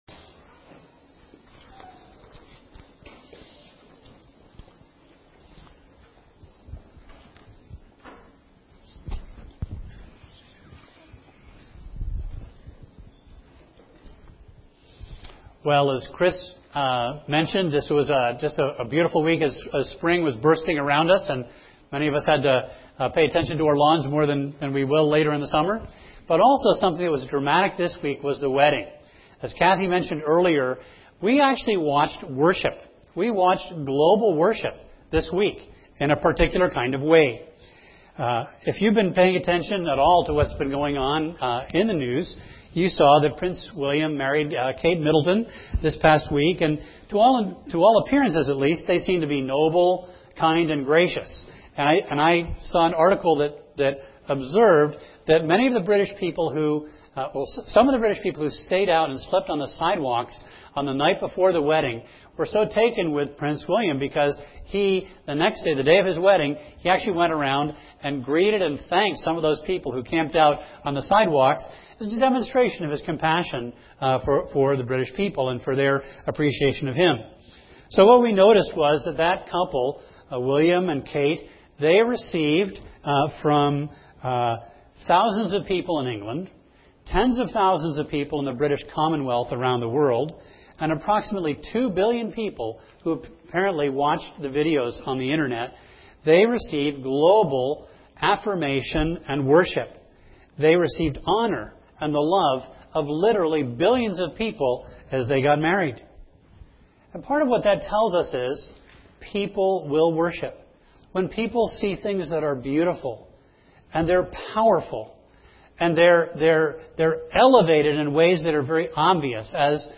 A message from the series "10 Commandments."